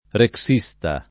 vai all'elenco alfabetico delle voci ingrandisci il carattere 100% rimpicciolisci il carattere stampa invia tramite posta elettronica codividi su Facebook rexista [ rek S&S ta ] s. m. e f. e agg. (stor.); pl. m. ‑sti